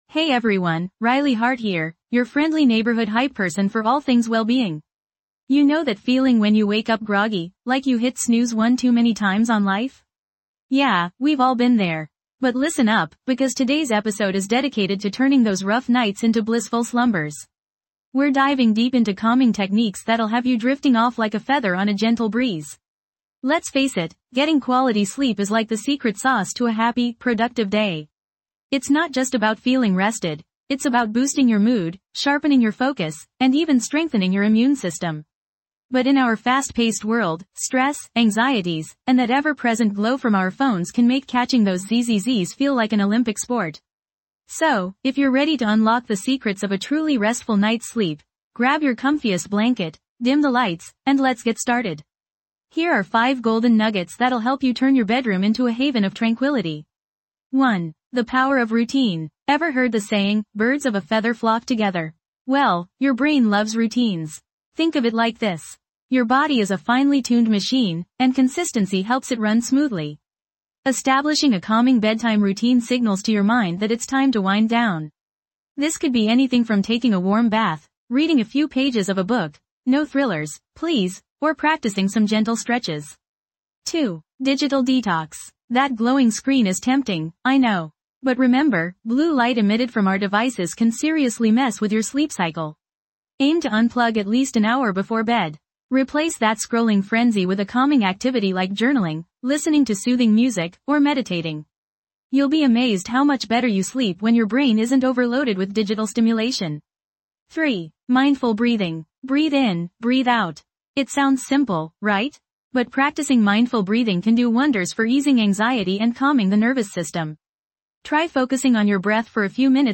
This podcast offers a soothing space for you to reconnect with your inner peace and cultivate a sense of well-being. Through carefully crafted affirmations delivered in a gentle, calming voice, we guide you towards reduced stress, enhanced focus, and a profound sense of ease.